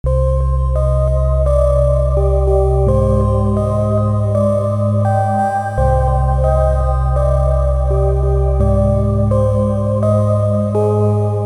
Download Scary Halloween sound effect for free.
Scary Halloween